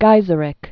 (gīzə-rĭk)